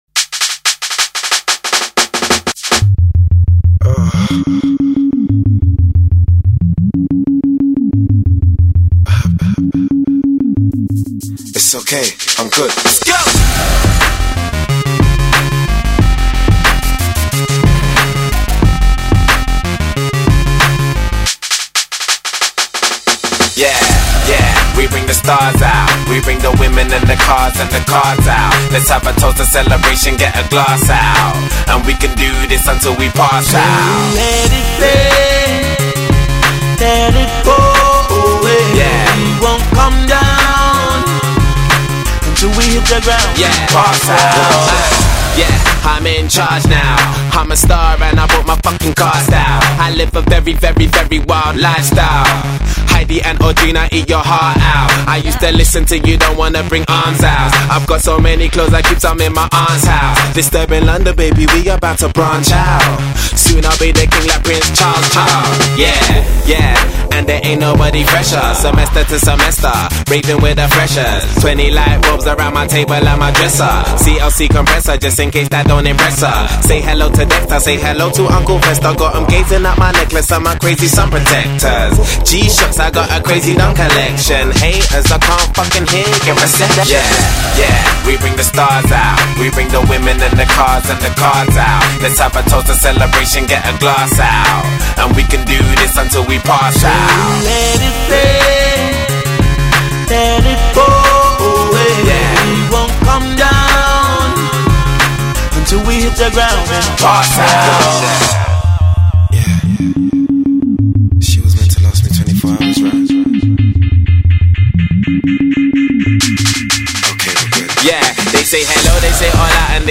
британского рэпера